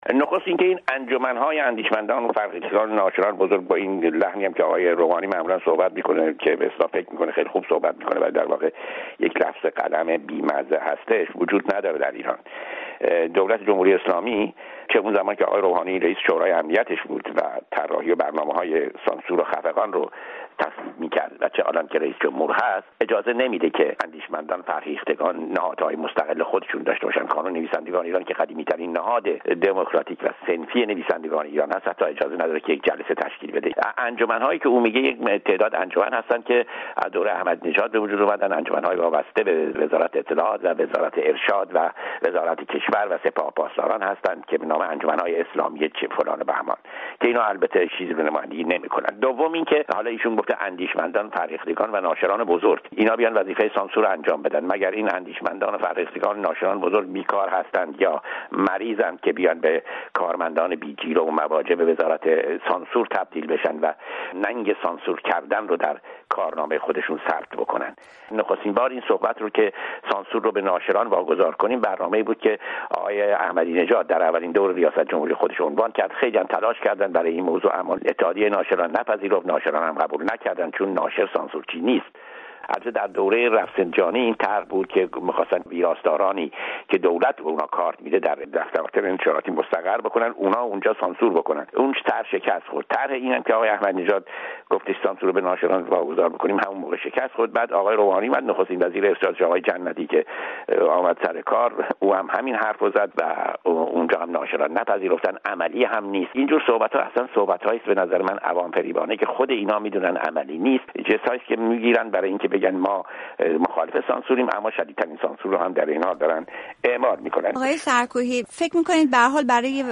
گفت‌وگو با فرج سرکوهی، تحلیلگر امور فرهنگی، درباره واگذاری سانسور کتاب‌ به انجمن‌ها و اندیشمندان